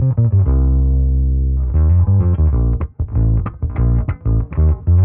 Index of /musicradar/dusty-funk-samples/Bass/95bpm
DF_PegBass_95-C.wav